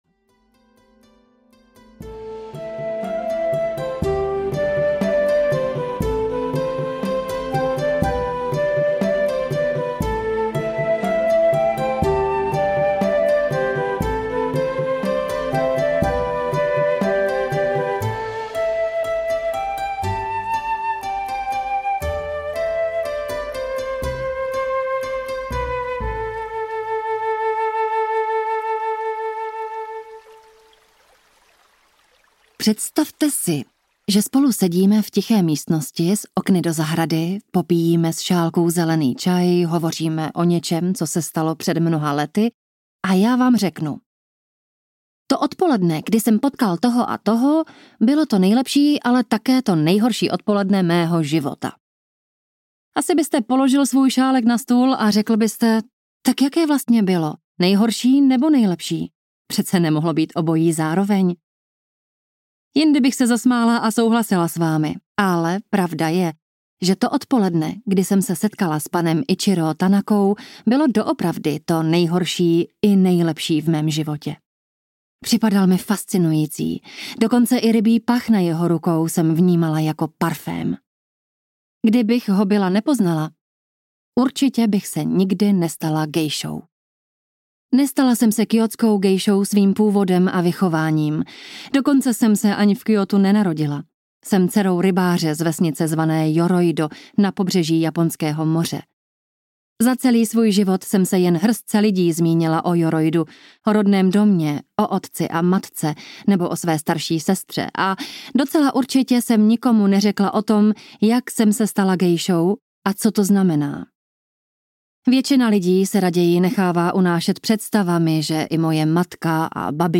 Gejša audiokniha
Ukázka z knihy